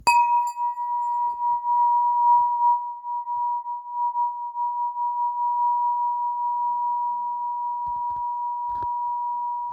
Le son s’étire doucement…
Une expérience sonore lumineuse et subtile
• la vibration est claire et cristalline
• les harmoniques se déploient naturellement dans l’espace
2⃣ Frappez délicatement la partie centrale avec le maillet fourni.
Cliquez ici pour découvrir la vibration du 963 Hz.
Matériau : aluminium acoustique traité thermiquement pour une résonance stable et claire
fréquence-963-Hz.mp3